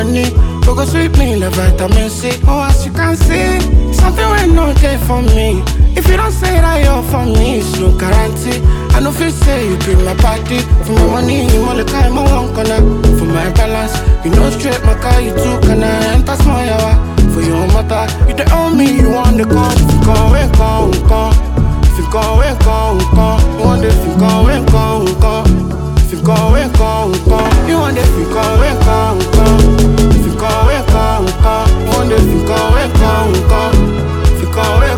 Жанр: Африканская музыка
# Afrobeats